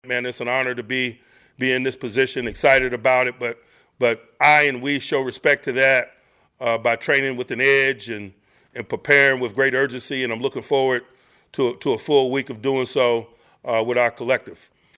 At his weekly media appearance, Tomlin said the playoff-clinching win over the Ravens featured some impressive play by the Steelers, but also had its “jayvee” moments.